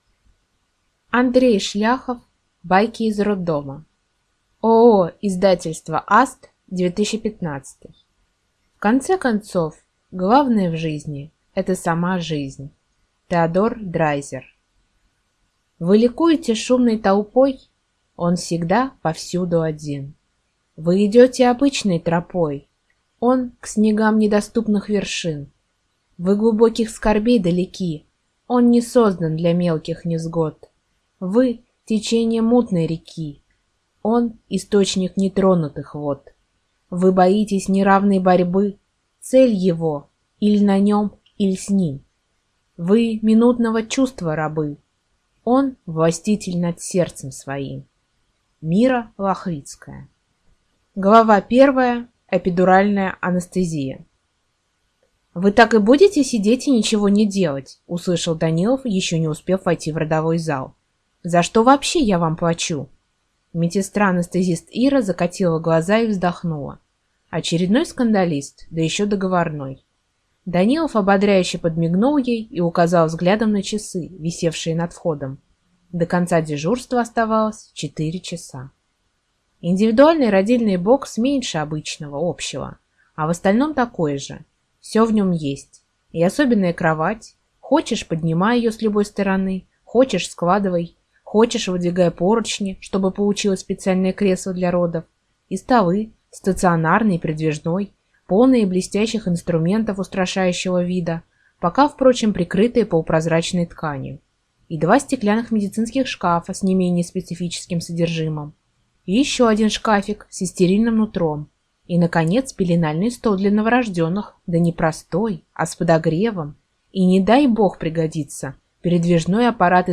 Аудиокнига Байки из роддома | Библиотека аудиокниг